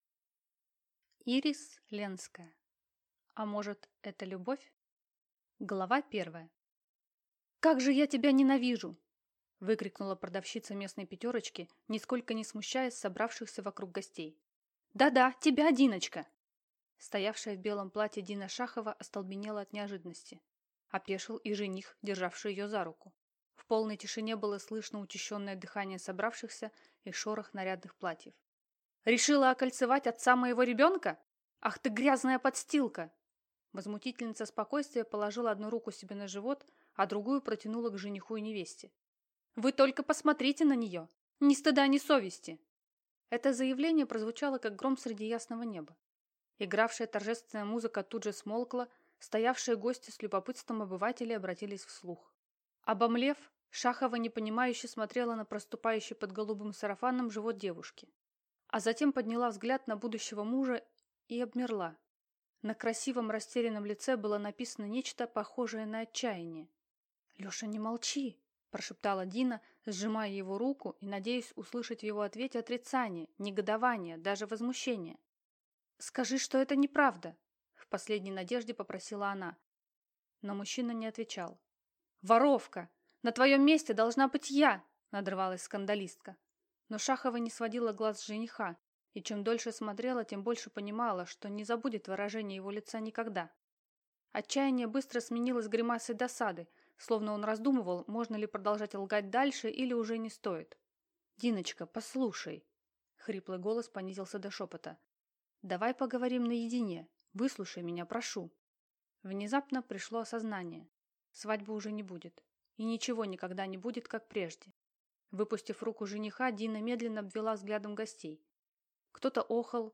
Аудиокнига А может, это любовь?